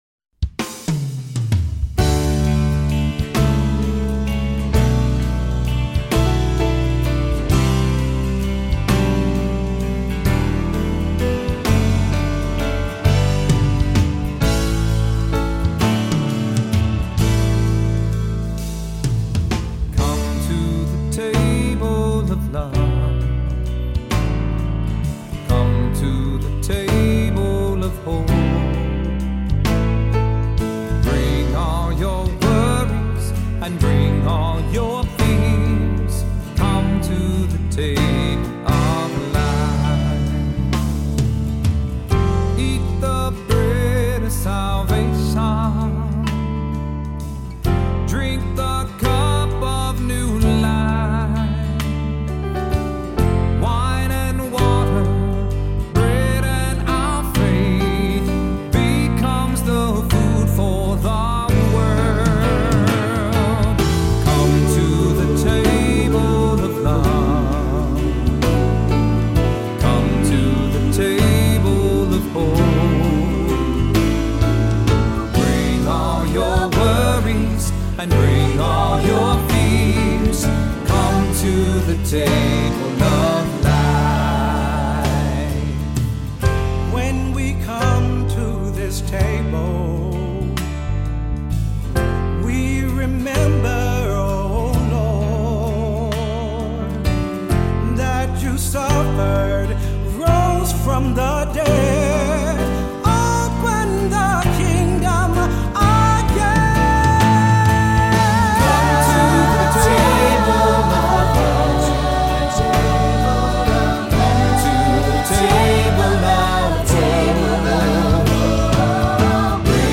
Voicing: SAB; Solo; Assembly